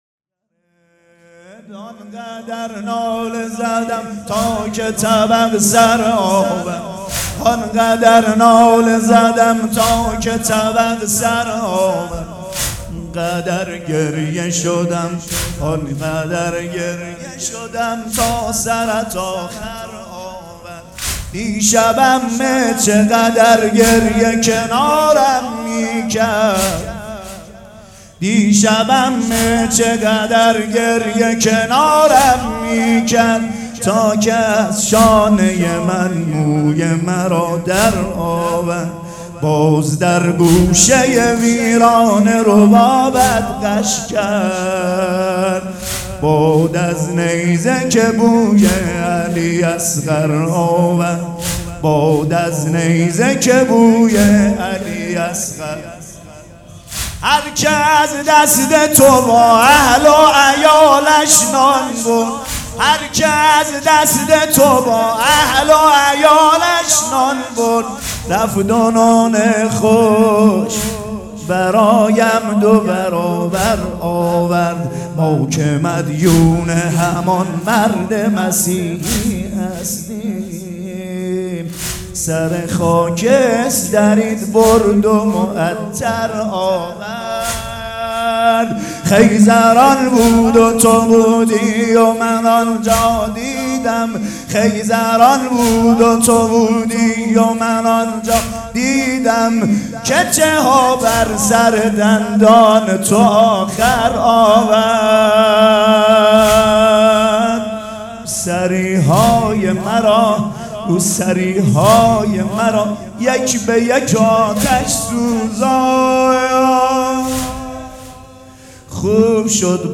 سبک اثــر واحد
مراسم عزاداری شب سوم